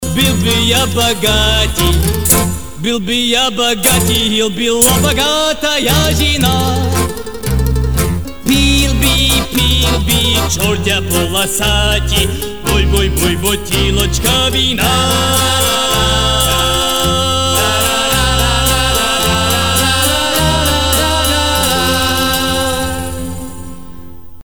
смешные
с акцентом
ретро